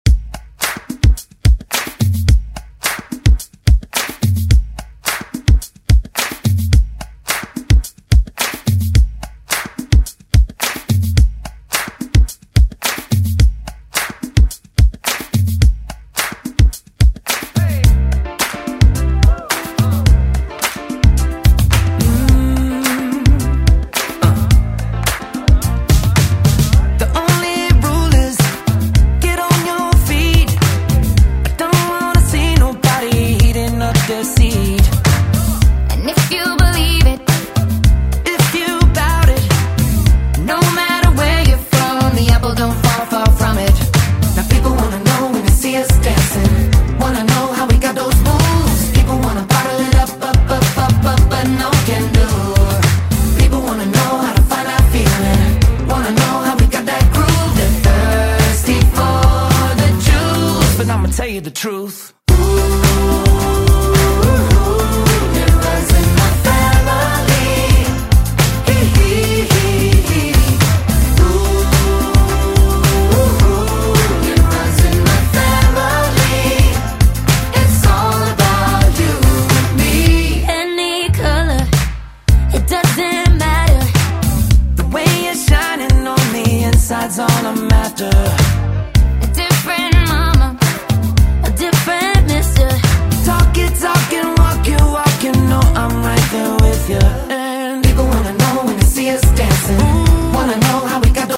Genres: LATIN , RE-DRUM , REGGAETON
Clean BPM: 93 Time